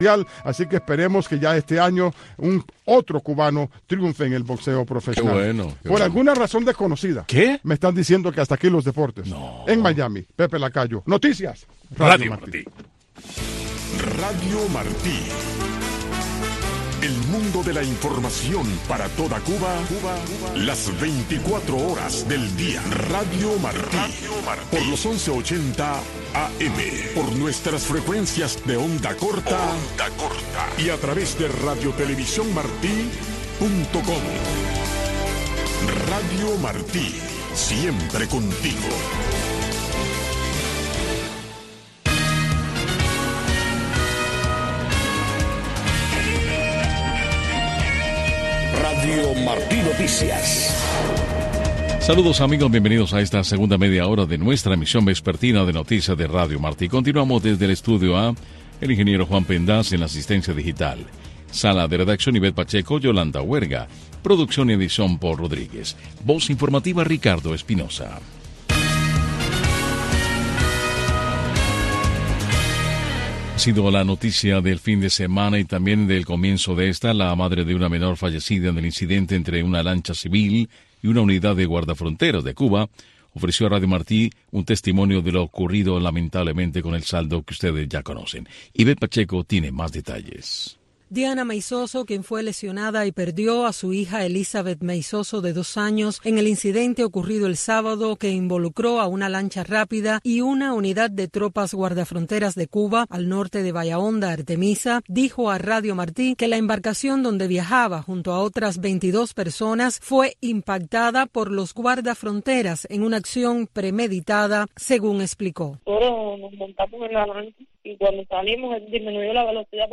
Noticiero Radio Martí presenta los hechos que hacen noticia en Cuba y el mundo